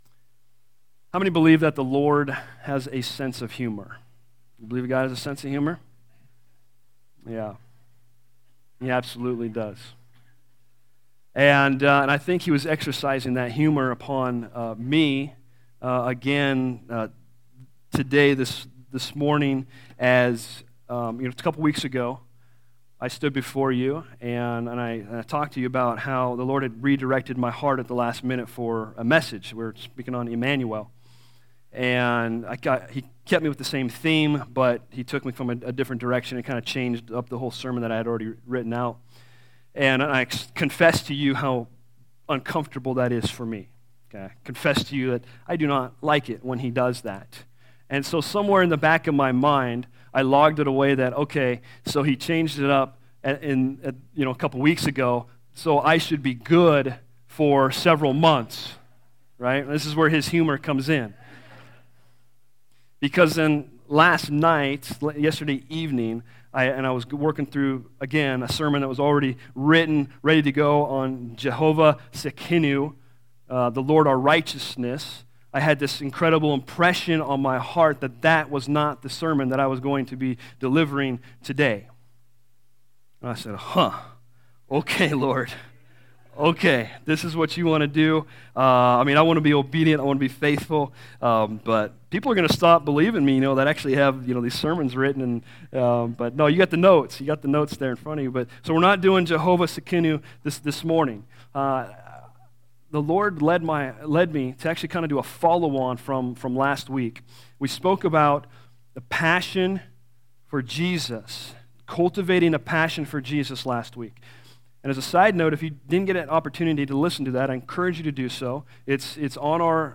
Bible Text: Psalm 16:8-11 | Preacher